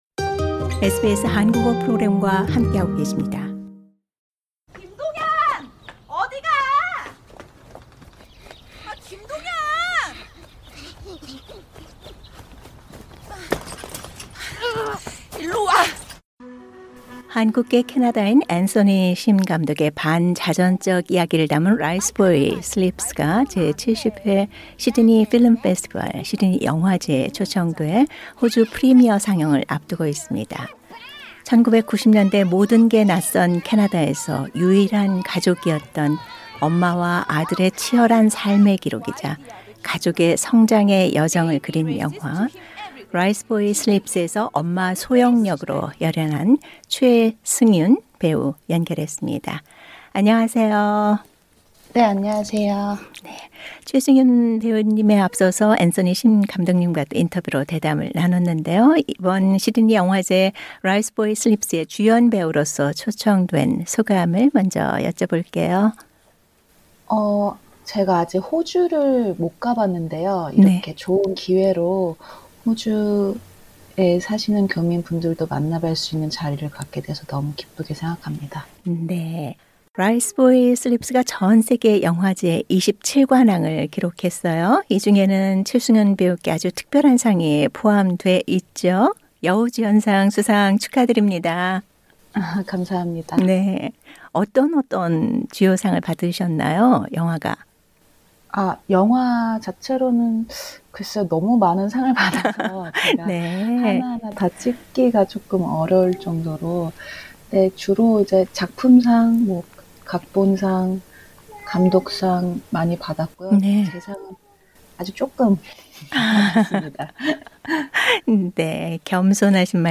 인터뷰: 70회 SFF 초청 ˈ라이스보이 슬립스(Riceboy Sleeps)ˈ 앤서니 심 감독